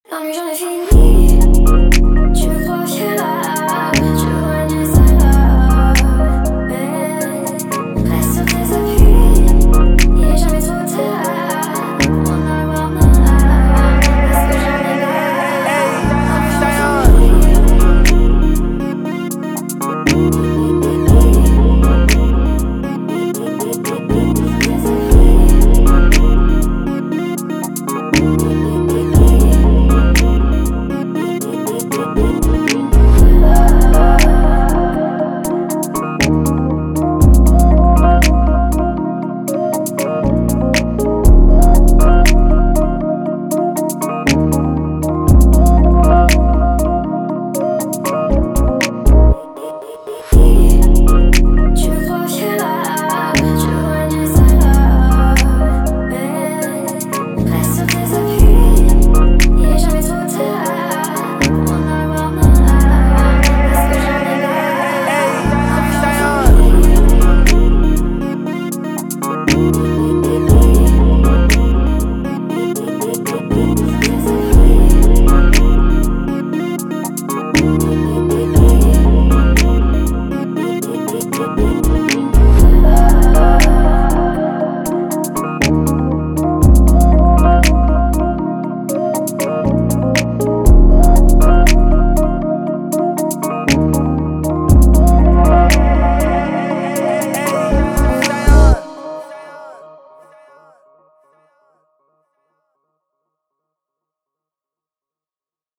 119 A Minor